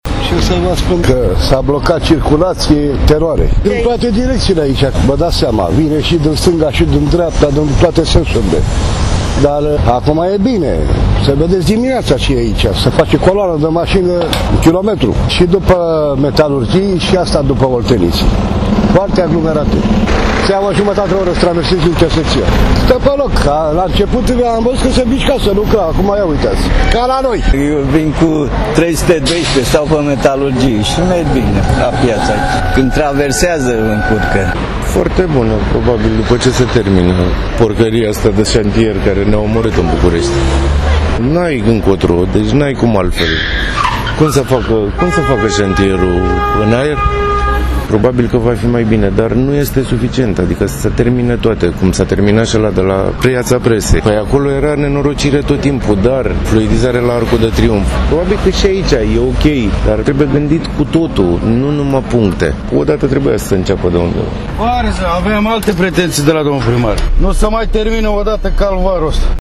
Ce spun oamenii din zonă despre lucrările la pasaj:
vox-pasaj-Big.mp3